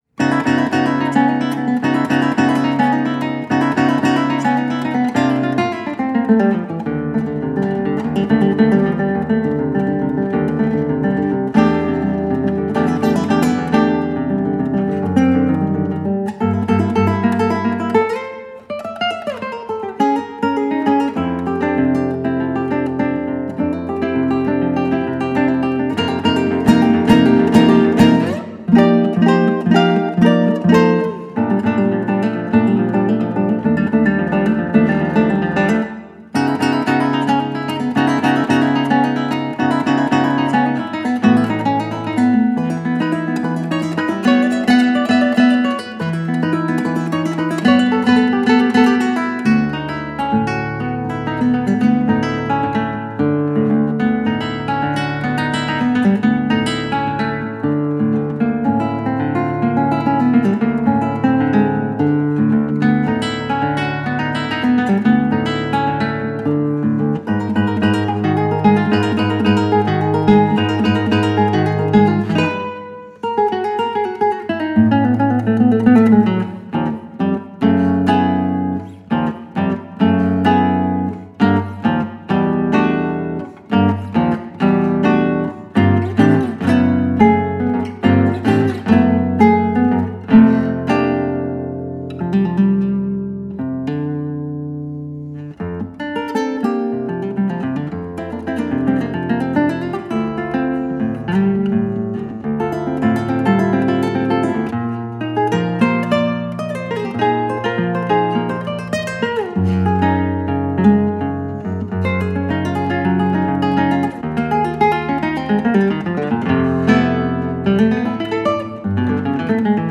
solo guitar music